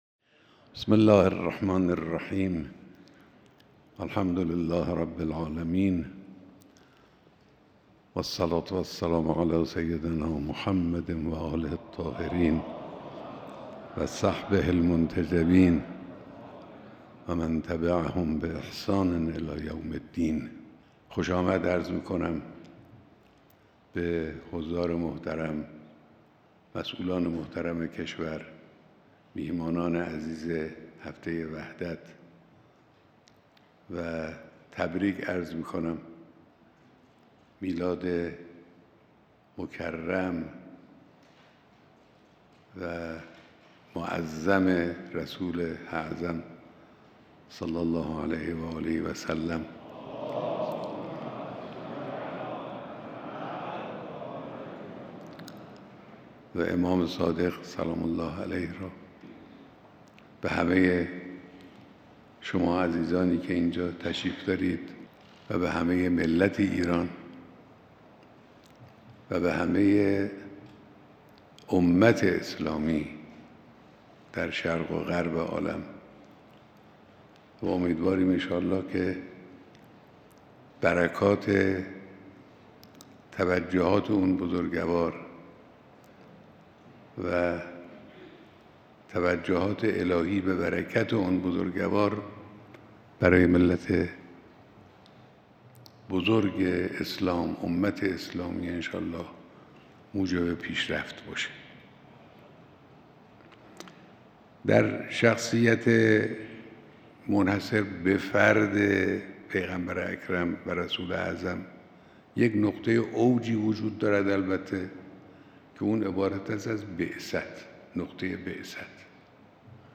بیانات در دیدار جمعی از مردم، مسئولان و مهمانان کنفرانس وحدت اسلامی